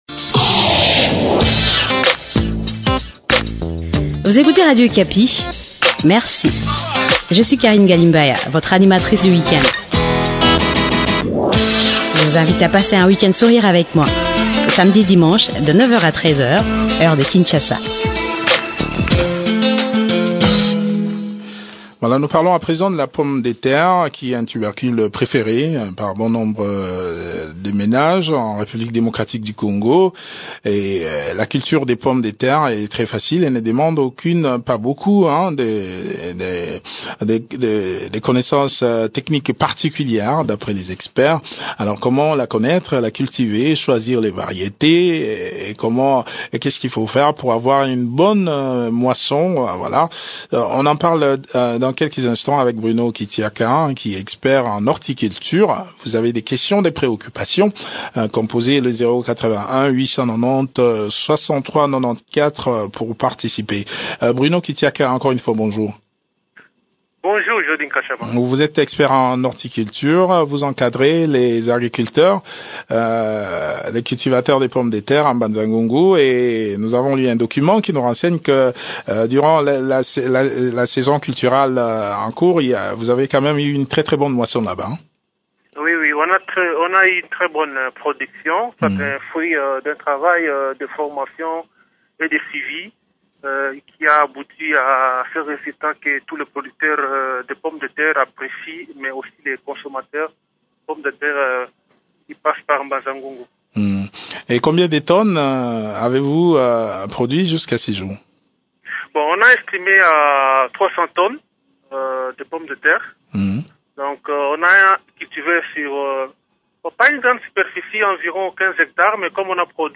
expert en horticulture urbaine et périurbaine.